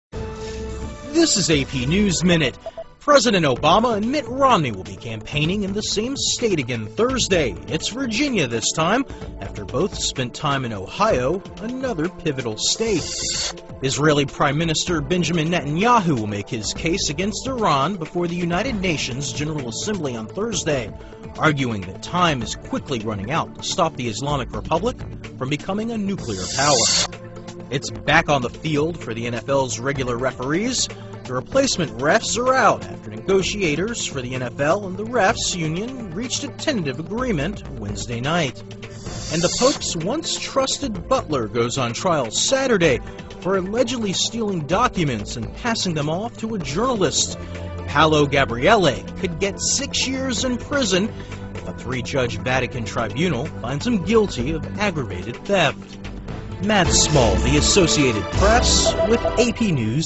在线英语听力室美联社新闻一分钟 AP 2012-10-05的听力文件下载,美联社新闻一分钟2012,英语听力,英语新闻,英语MP3 由美联社编辑的一分钟国际电视新闻，报道每天发生的重大国际事件。